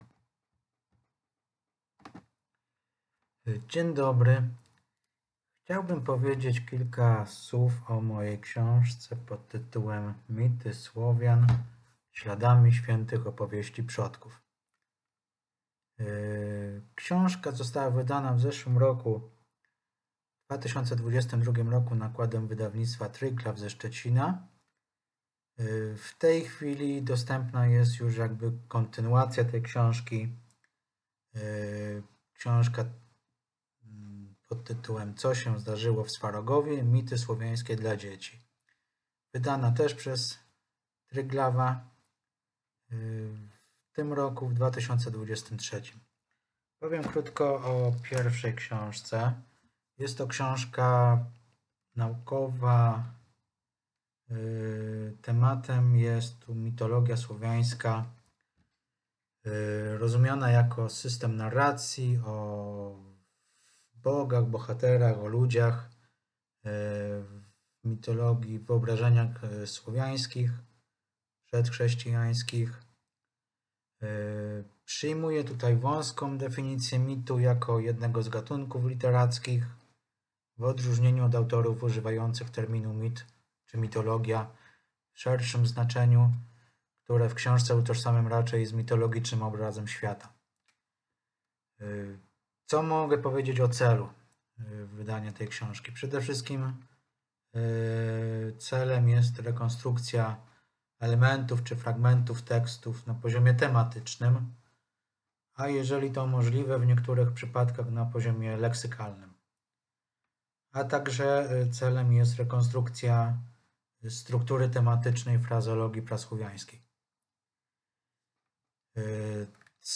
виртуальная встреча в организации Комиссии по фольклористике МКС